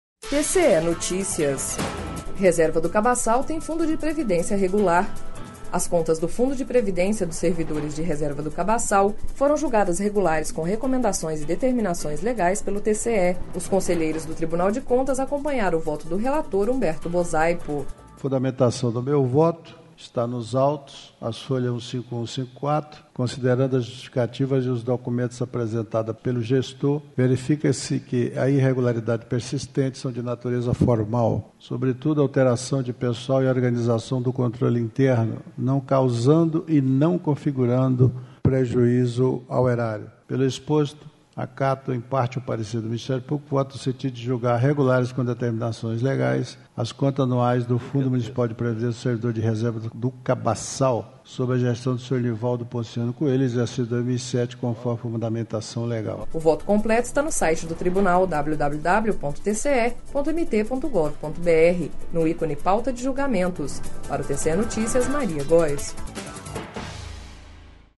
Sonora: Humberto Bosaipo - conselheiro do TCE